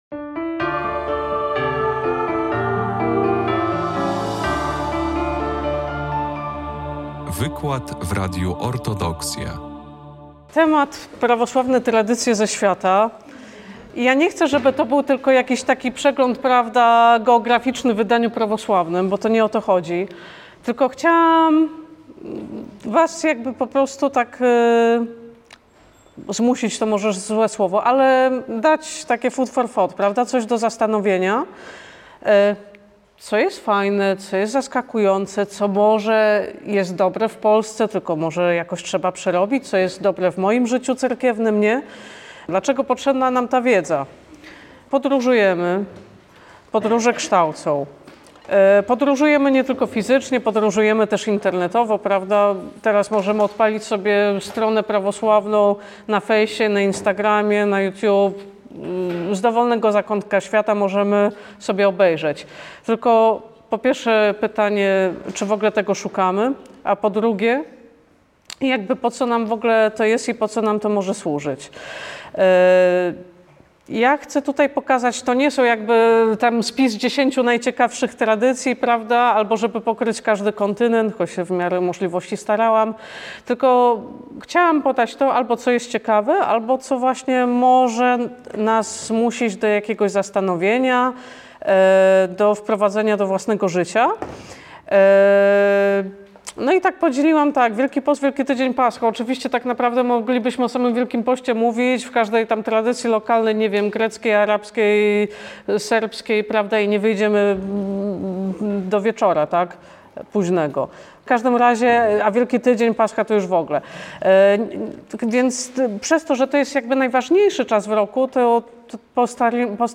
Spotkanie odbyło się 22 października 2024 roku w Centrum Kultury Prawosławnej w Białymstoku w ramach cyklu spotkań dla młodzieży szkół średnich i studentów To My.